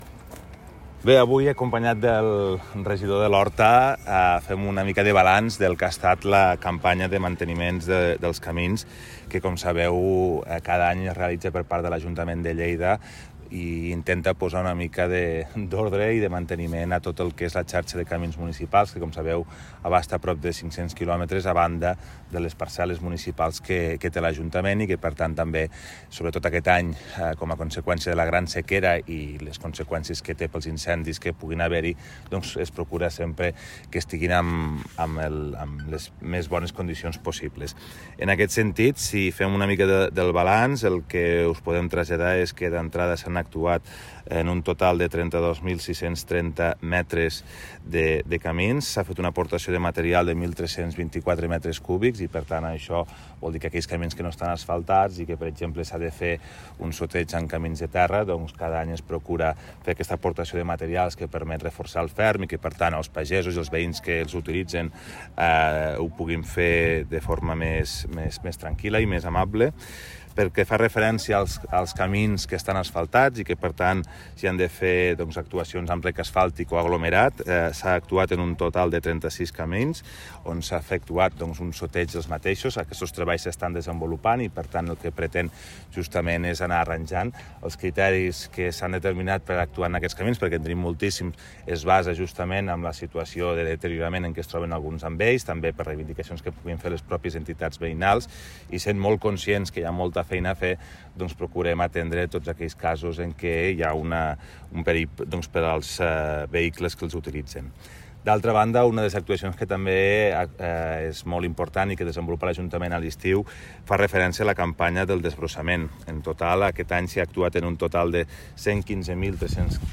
tall-de-veu-toni-postius